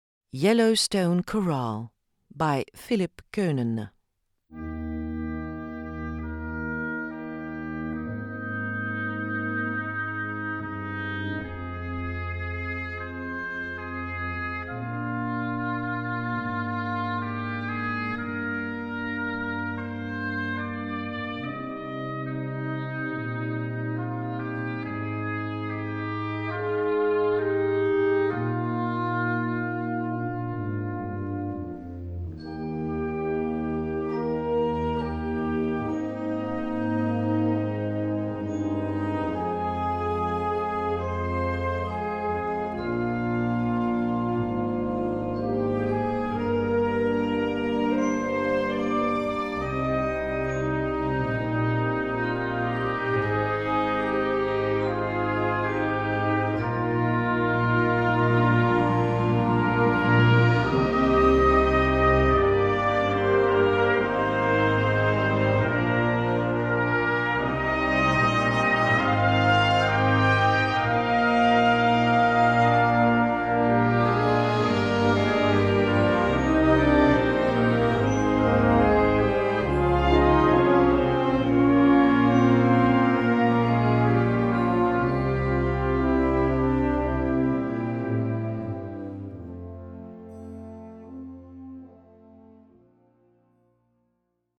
Gattung: Choral für Blasorchester
Besetzung: Blasorchester